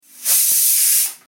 hover_cooker_whistle.mp3